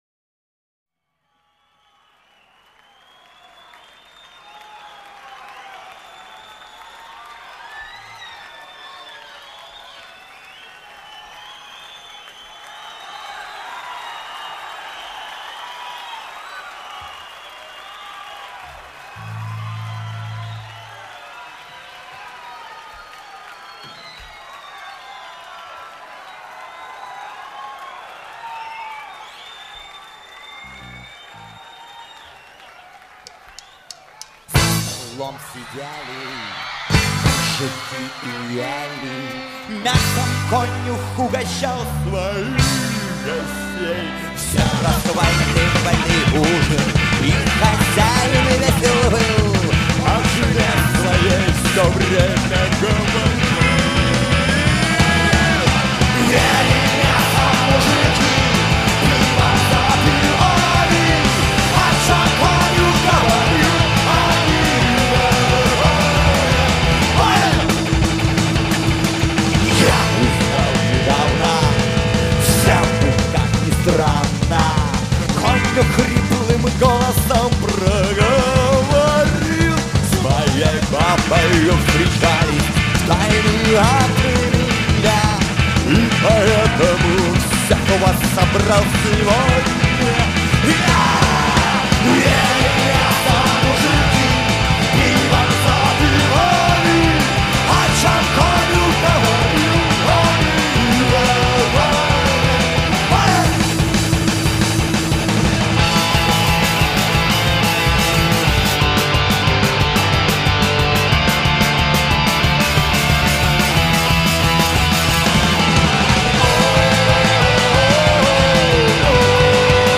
Категория: Рок